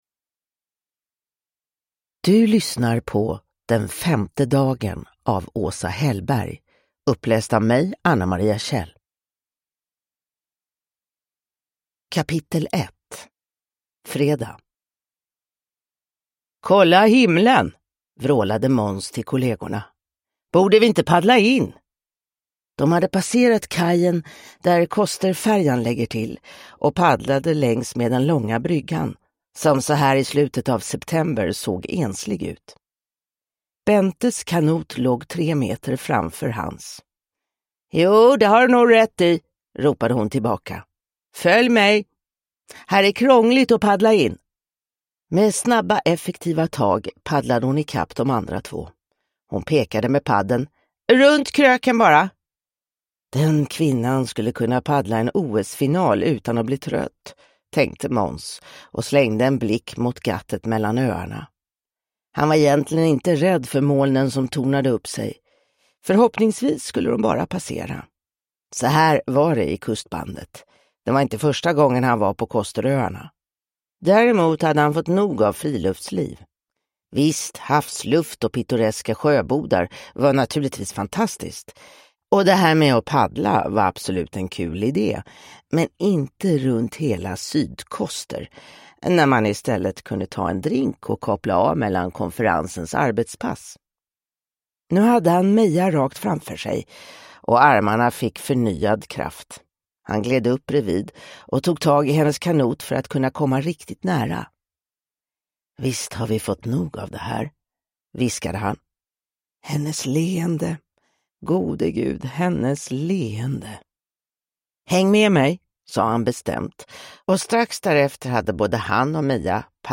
Den femte dagen – Ljudbok